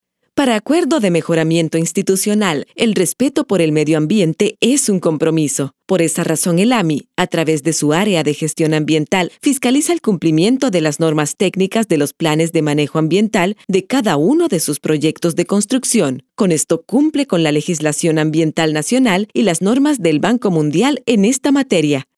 More than 25 years of experience recording commercials, e-learning, radio news, theatre, documentals, etc. in spanish latin american neutral.
I MAC with Pro Tools AKG 4000 microphone Pre amp Tube Bellari, DBX Compressor, Module Aphex
Sprechprobe: Industrie (Muttersprache):